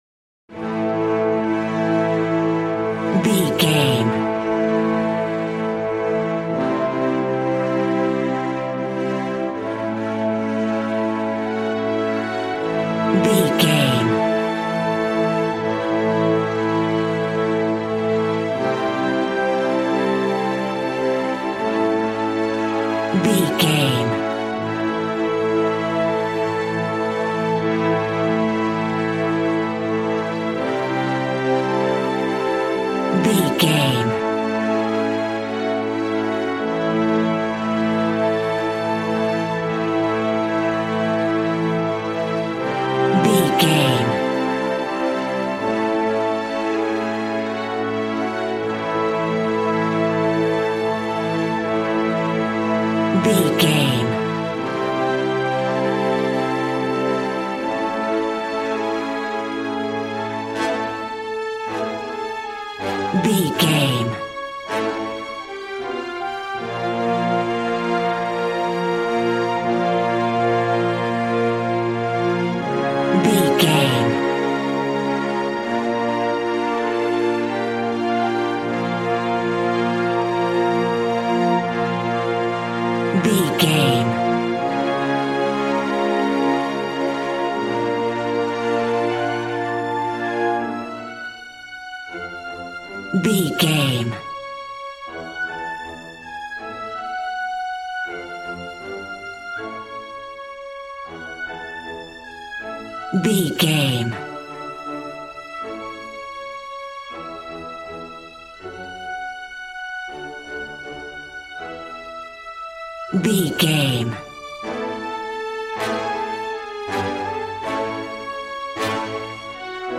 Regal and romantic, a classy piece of classical music.
Ionian/Major
regal
cello
double bass